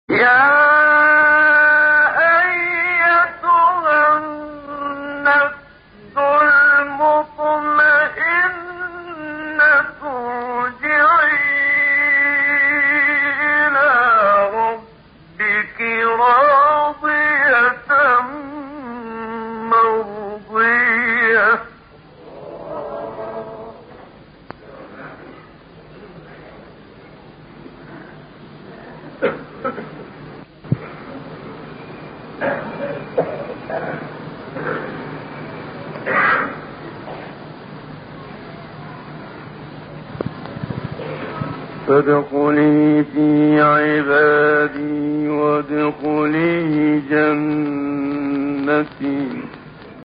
طنین آیات پایانی سوره فجر با صدای محبوب‌ترین قاریان مصری